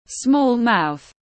Miệng nhỏ tiếng anh gọi là small mouth, phiên âm tiếng anh đọc là /smɔːl maʊθ/ .